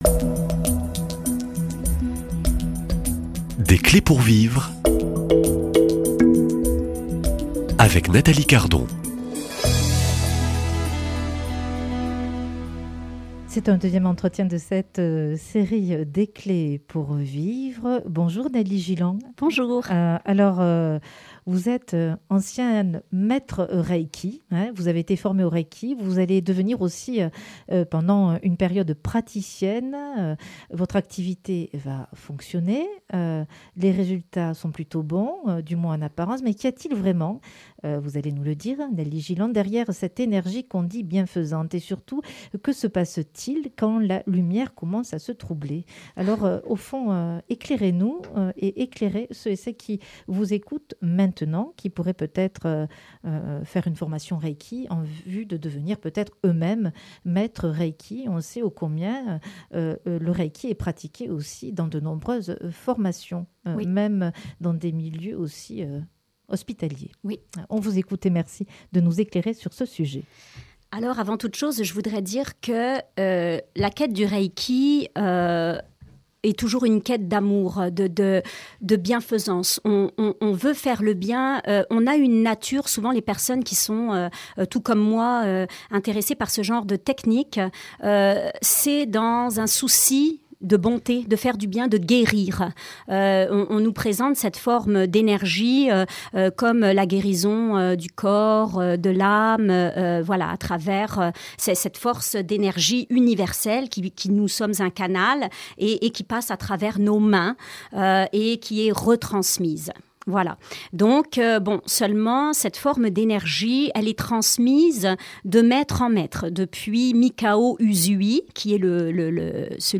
Accueil \ Emissions \ Foi \ Témoignages \ Des clés pour vivre \ L’énergie, la maîtrise...et l’illusion ?